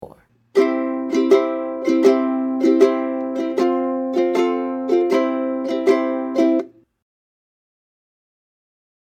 Voicing: Ukulele Me